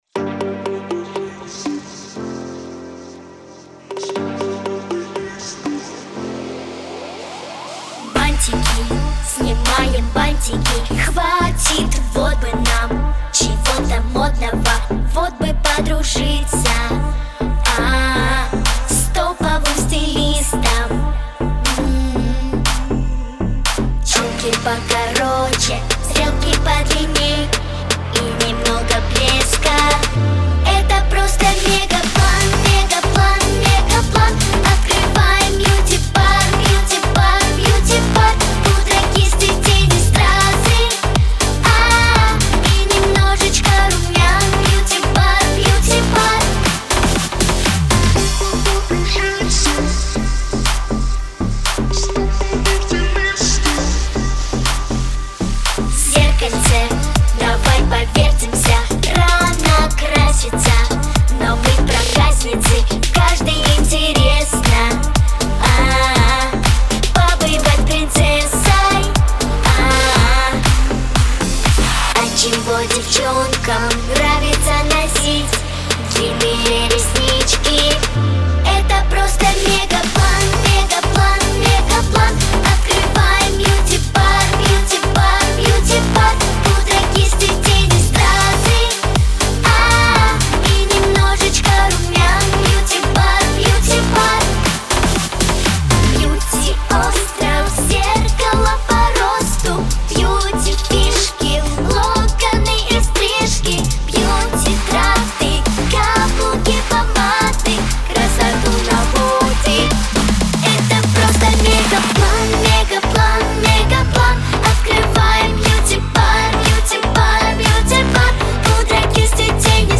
Современная яркая весёлая песня про юных модниц.
Характер песни: весёлый.
Темп песни: быстрый.
Диапазон: Си♭ малой октавы - До второй октавы.